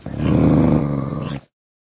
mob / wolf / growl1.ogg
growl1.ogg